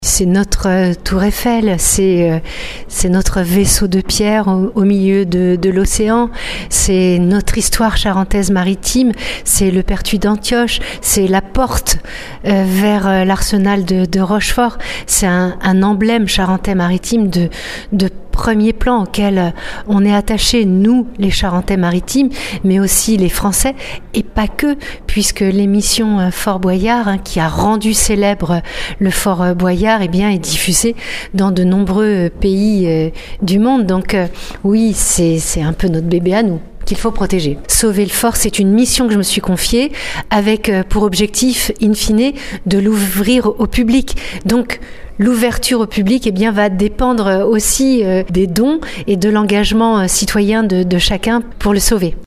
Signature ce matin sur le fort entre le Département de la Charente-Maritime et la Fondation du patrimoine.
On écoute la présidente de la Charente-Maritime Sylvie Marcilly :